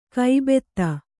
♪ kai betta